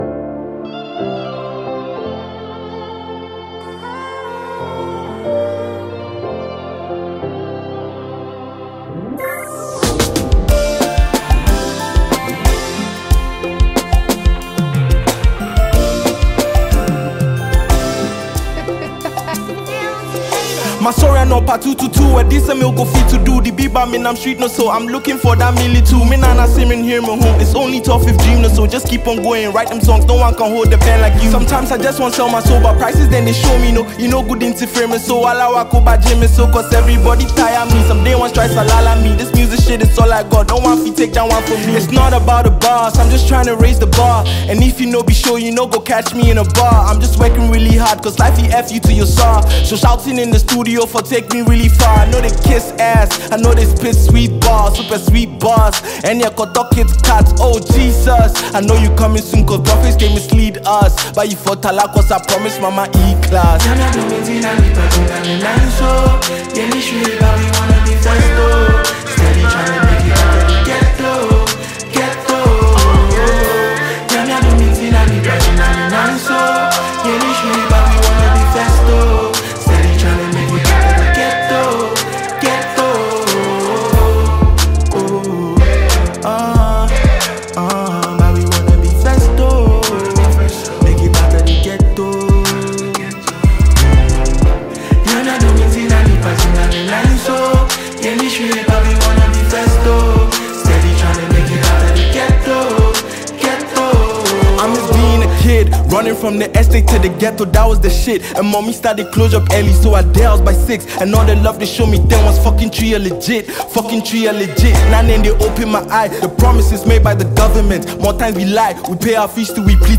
soul-stirring new single
Genre: Afro-Gospel